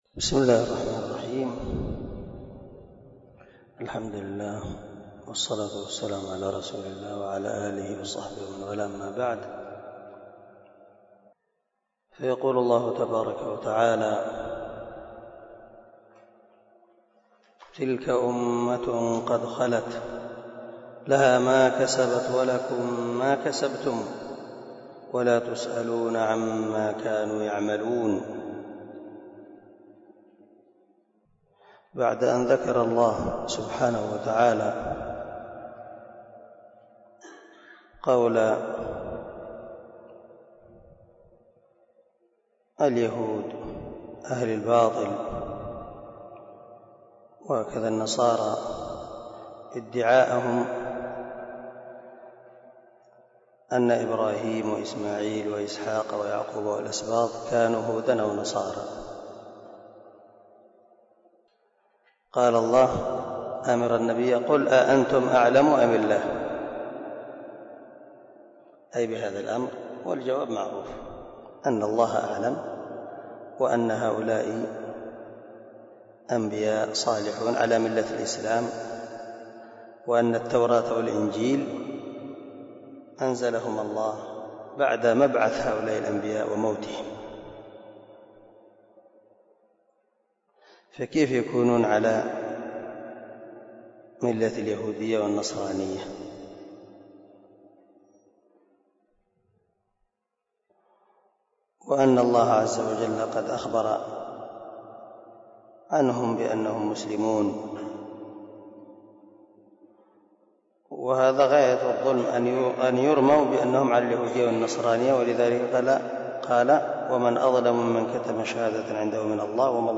059الدرس 49 تفسير آية ( 142 ) من سورة البقرة من تفسير القران الكريم مع قراءة لتفسير السعدي